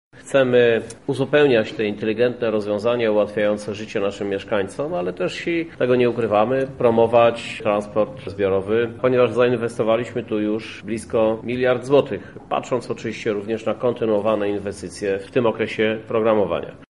-mówi Krzysztof Żuk, prezydent Lublina.